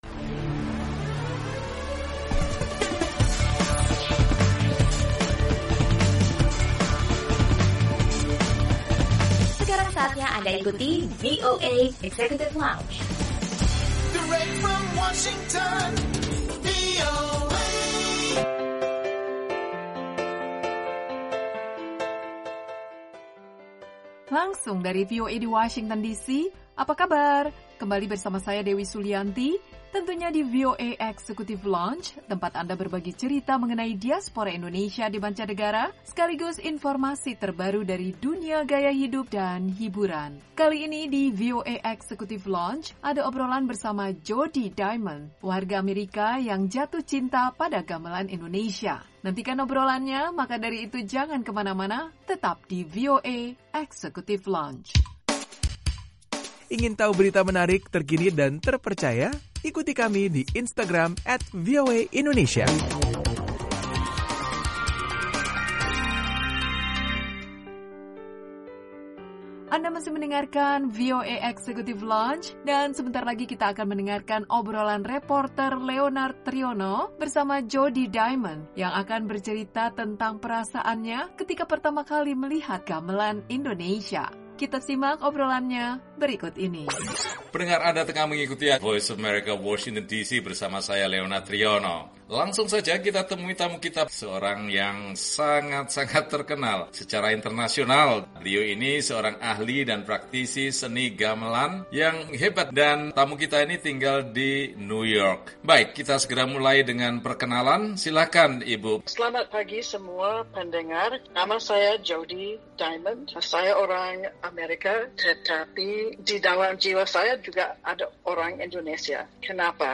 Obrolan